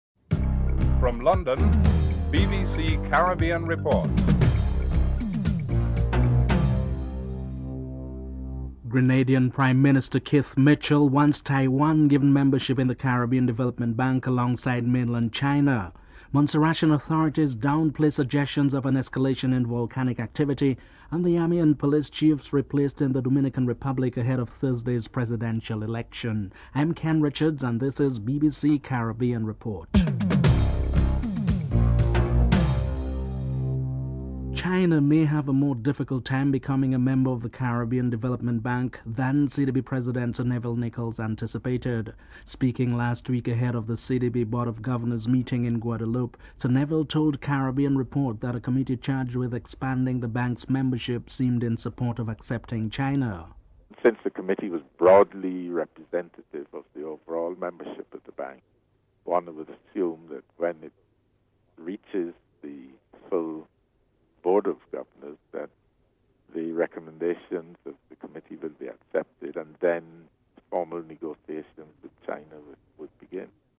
1. Headlines (00:00-00:32)